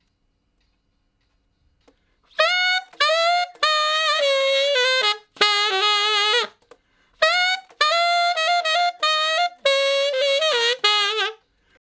068 Ten Sax Straight (Ab) 09.wav